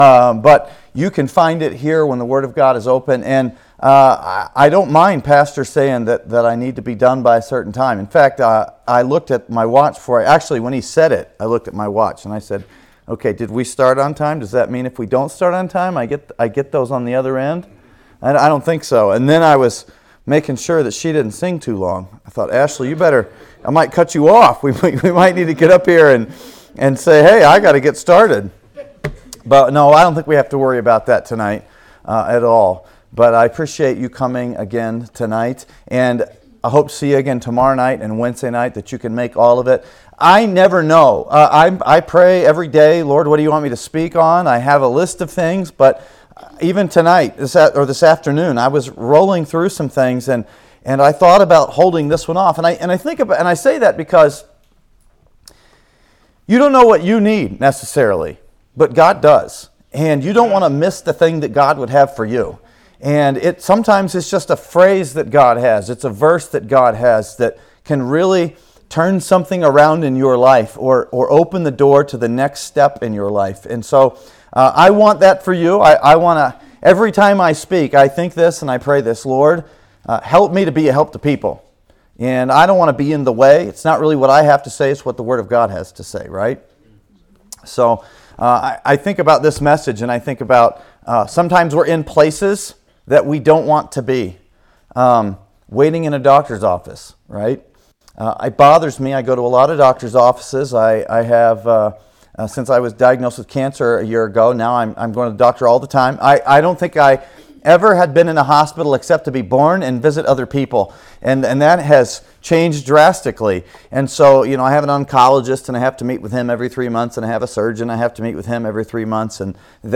Sermons | Grace Baptist Church
Revival-Service-3.mp3